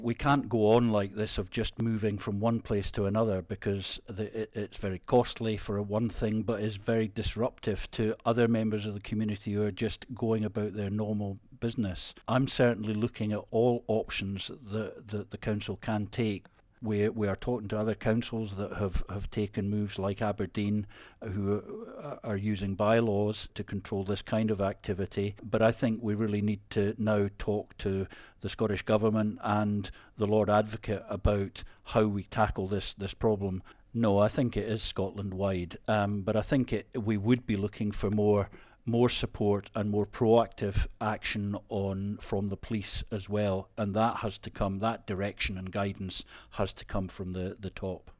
Council leader David Ross says the current situation is untenable: